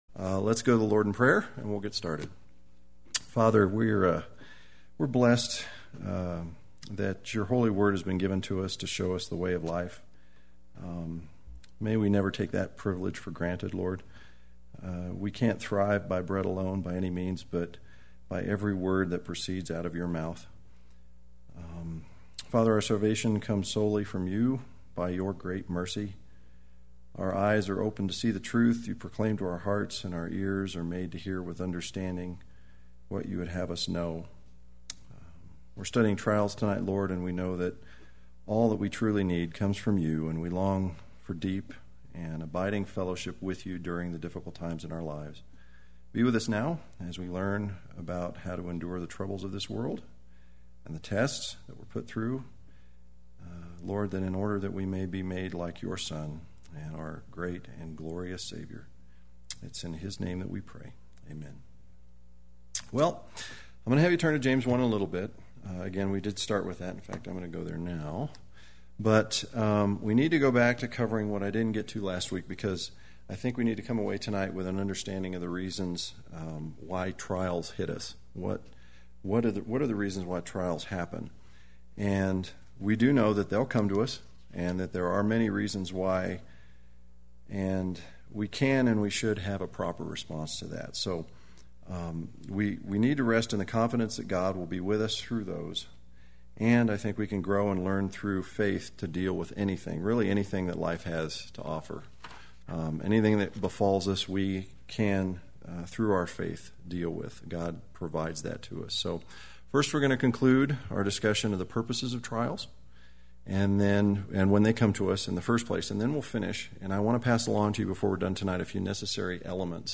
Play Sermon Get HCF Teaching Automatically.
Part 2 Wednesday Worship